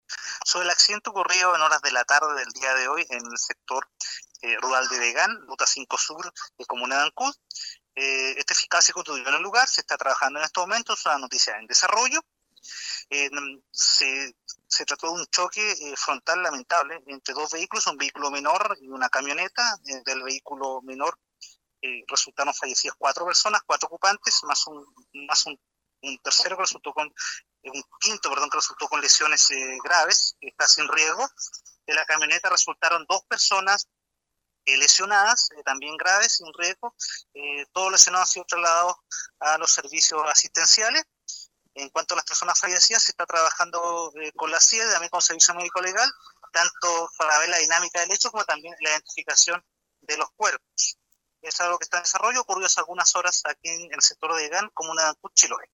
El fiscal de turno, Javier Calisto, que llegó al sitio del suceso, confirmó la muerte de los ocupantes del jeep, en tanto de la camioneta afirmó hubo solo lesionados.
FISCAL-ACCIDENTE.mp3